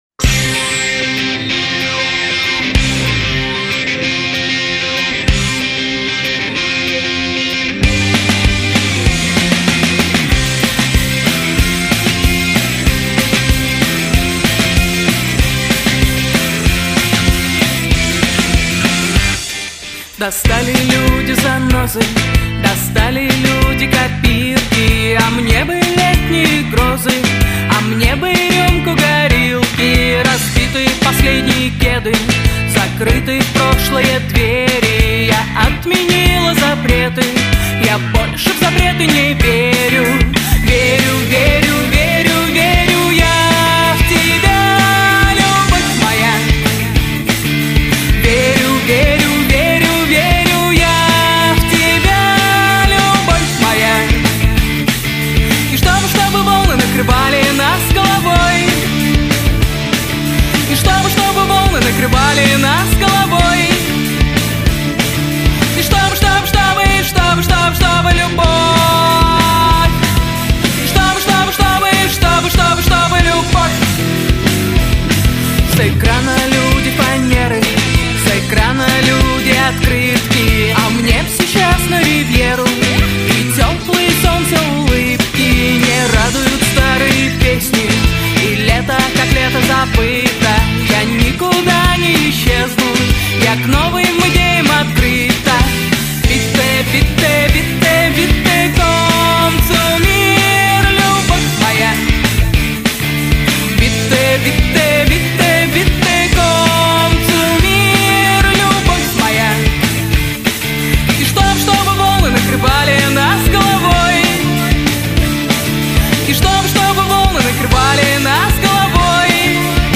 Категория: Застольные песни